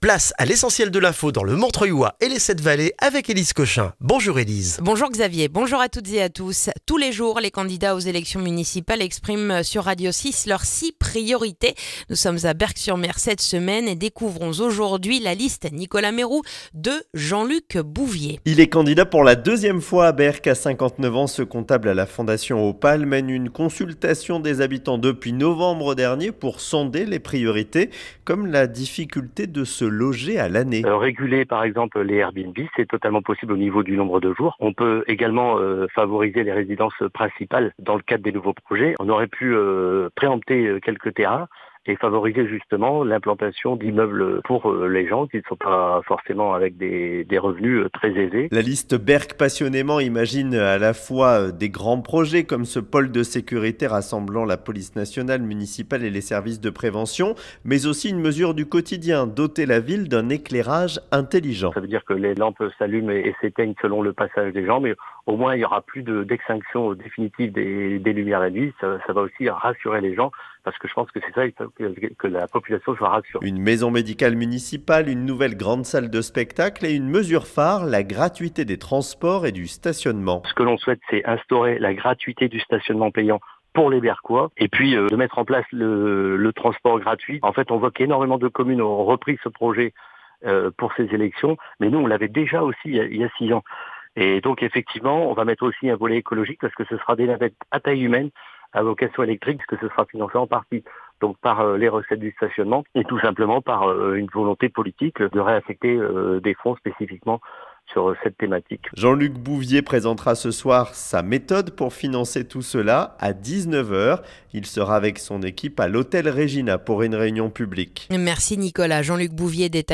Le journal du mardi 3 mars dans le montreuillois